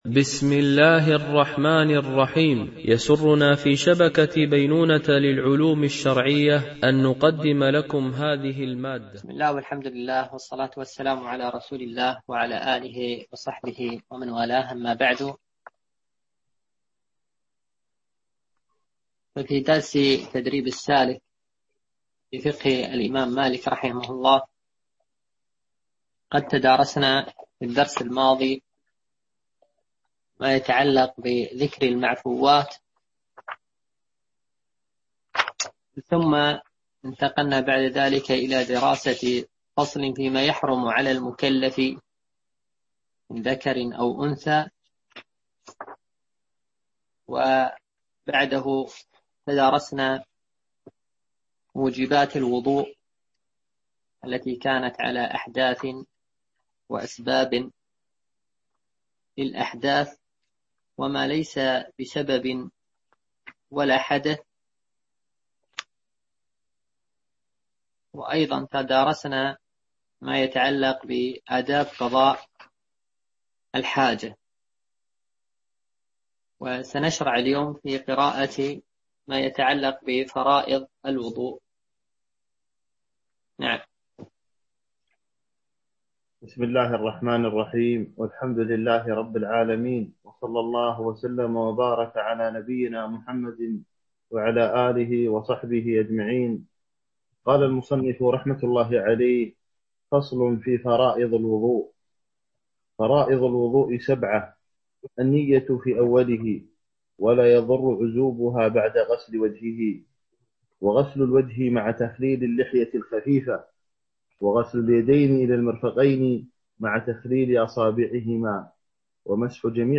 شرح الفقه المالكي ( تدريب السالك إلى أقرب المسالك) - الدرس 5 ( كتاب الطهارة )